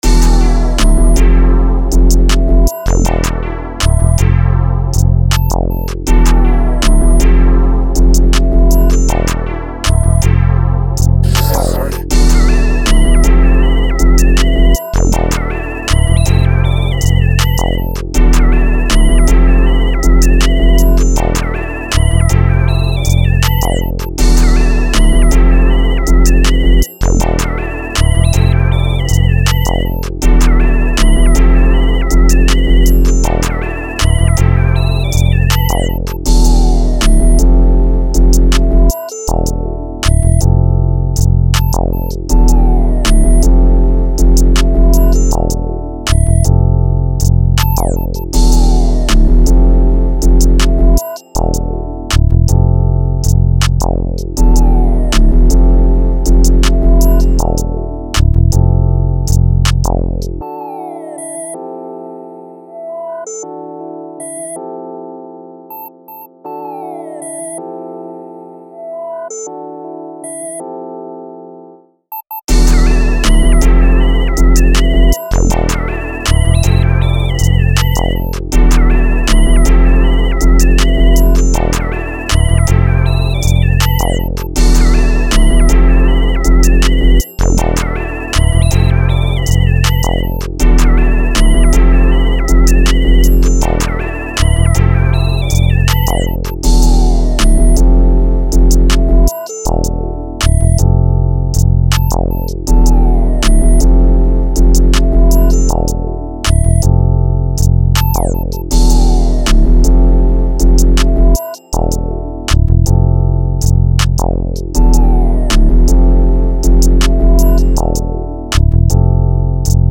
Untitled (Drum Kit)
has everything you need to make unique new jazz and hoodtrap beats from scratch! this kit really stands out with its unique 808s and lead oneshots.